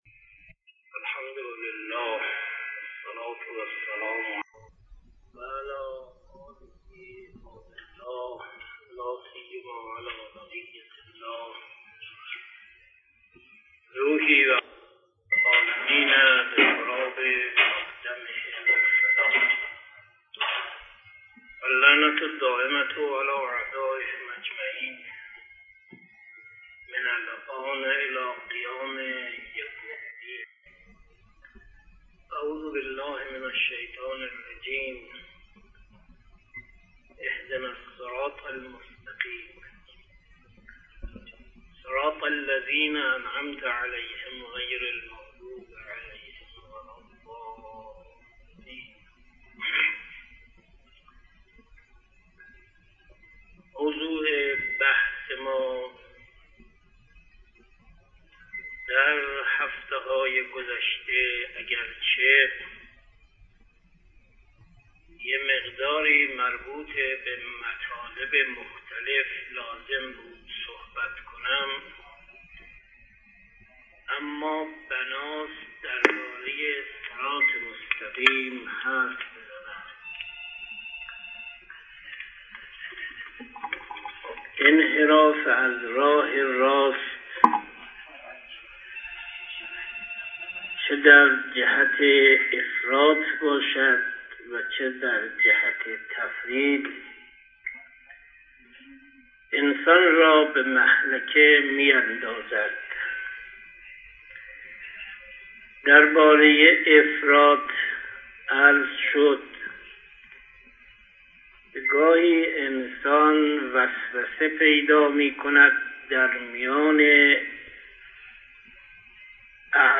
استاد معظم در این مجلس در موضوع صراط مستقیم و پرهیز از افراط و تفریط در عقاید و اعمال به ایراد سخن پرداختند .